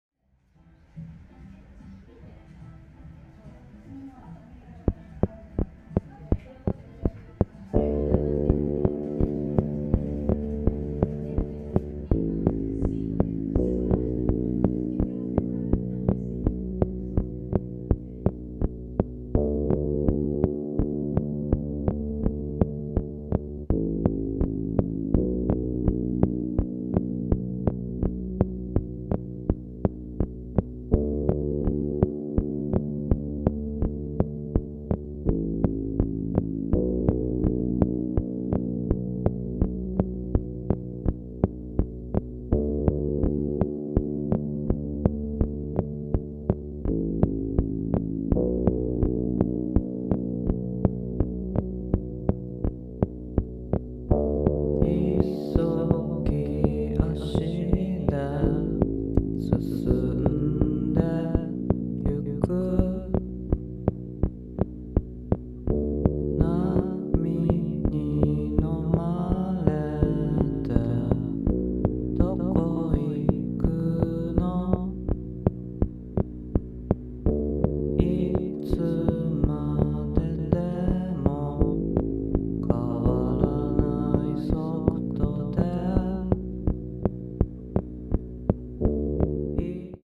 宅録ユニット
ロウファイ・エレクトロな歌モノ作品！
気怠さと儚さがないまぜになったような雰囲気に引き込まれますよ！